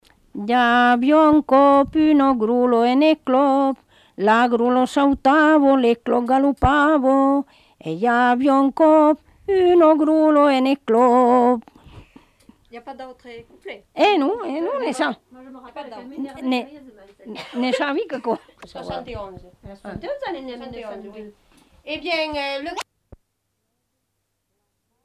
Aire culturelle : Haut-Agenais
Lieu : Laugnac
Genre : forme brève
Effectif : 1
Type de voix : voix de femme
Production du son : chanté
Classification : formulette enfantine